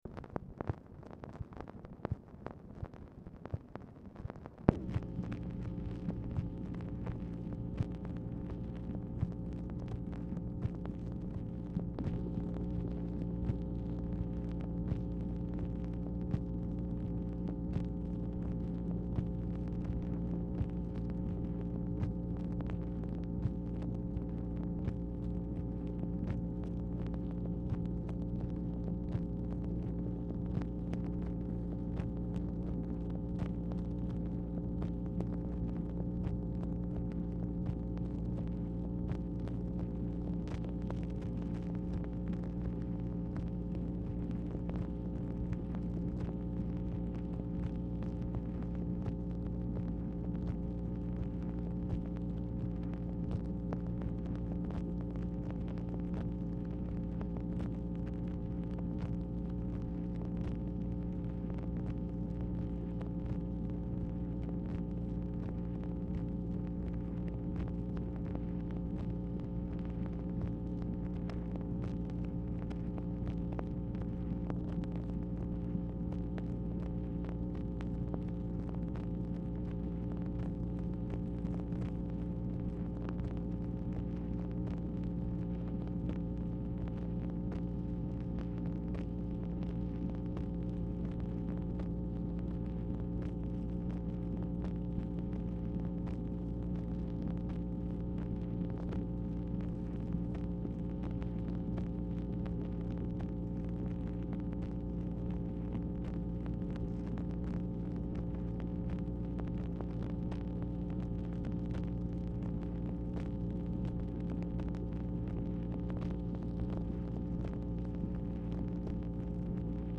Telephone conversation # 11044, sound recording, MACHINE NOISE, 11/15/1966, time unknown | Discover LBJ